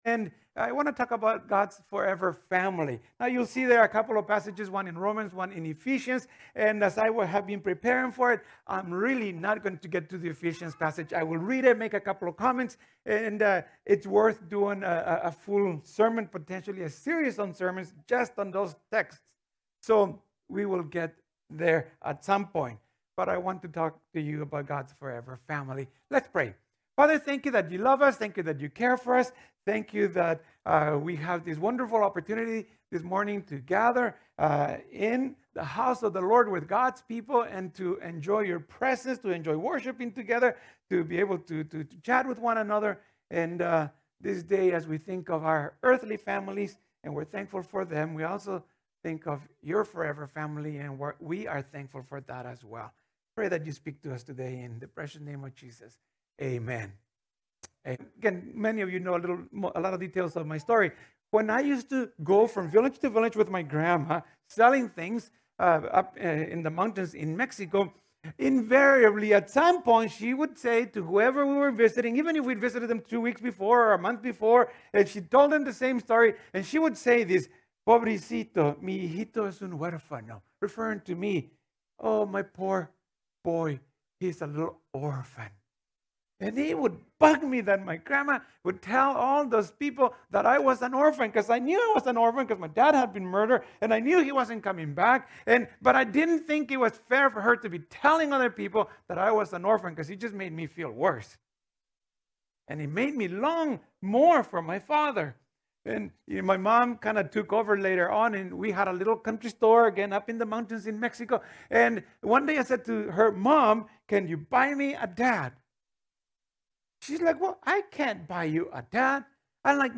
Sermons | Devon Community Church
It was preached on the Sunday of the Family Day long weekend in Alberta]